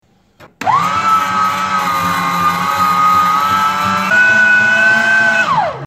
Звуки мясорубки
Звук работающей электромясорубки для монтажа